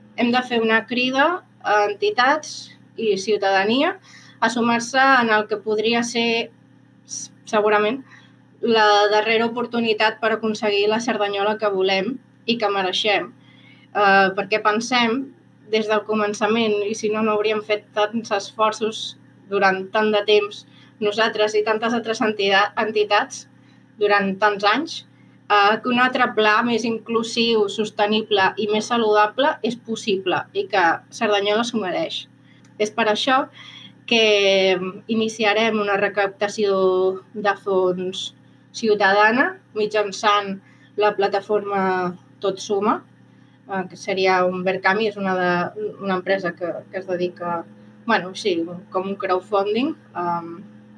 Declaracions
en roda de premsa